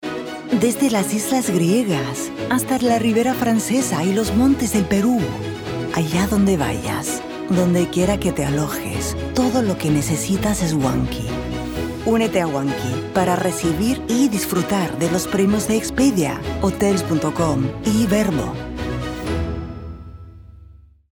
A voice of dusky warmth, intelligence, and effortless elegance—created for brands that wish to be felt as much as heard.
Castilian & International Spanish - Corporate & Global Communication
From business concepts to global travel experiences— this Spanish demo moves effortlessly between precision and warmth, offering clarity, credibility, and a naturally engaging tone.
Recording from a professional Studiobricks home studio in Barcelona.